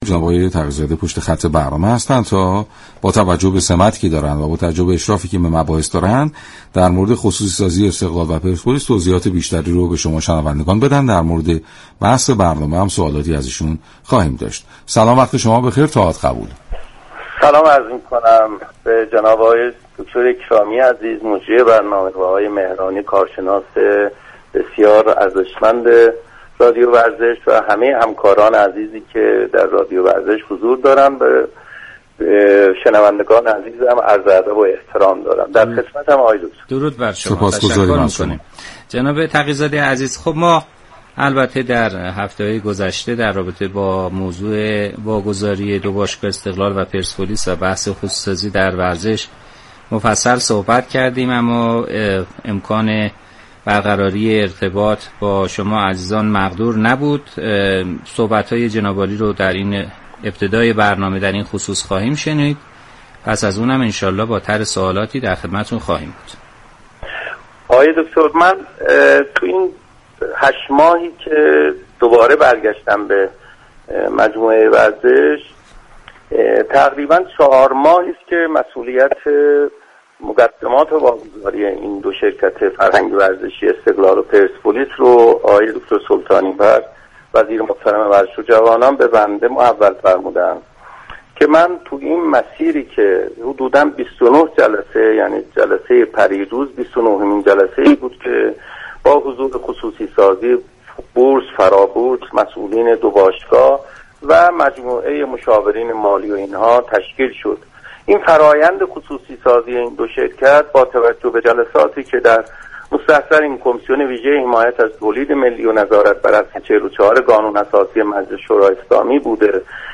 برنامه «همیشه با ورزش» رادیو ورزش چهارشنبه 17 اردیبهشت با حضور جمشید تقی‌زاده، معاون حقوقی، امور مجلس و هماهنگی امور استان‌های وزارت ورزش و جوانان به موضوع خصوصی سازی باشگاه های استقلال و پرسپولیس پرداخت.
شما می توانید از طریق فایل صوتی پیوست شنونده گفتگوی كامل جمشید تقی‌زاده، معاون حقوقی، امور مجلس و هماهنگی امور استان‌های وزارت ورزش و جوانان با رادیو ورزش باشید.